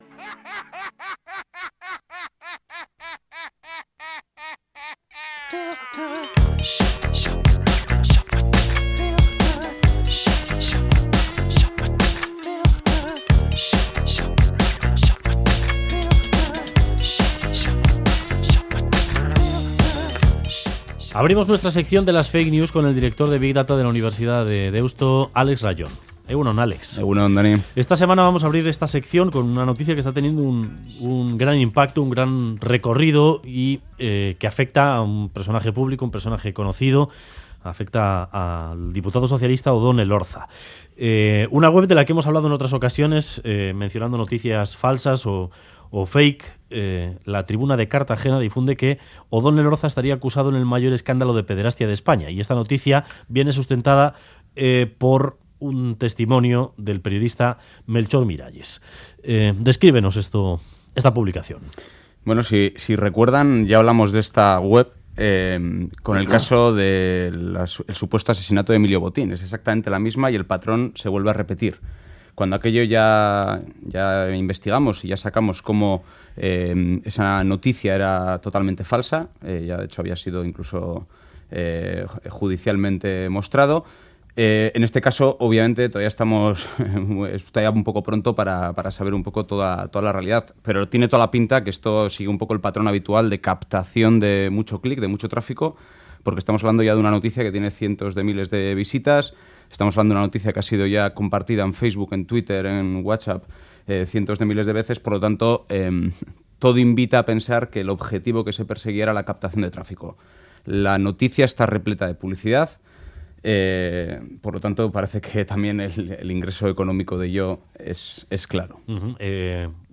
Audio: El diputado socialista responde en Radio Euskadi al periodista Melchor Miralles, que lo acusa por su supuesta implicación en un escándalo de pederastia. Anuncia una querella criminal al sentirse "desbordado por injurias"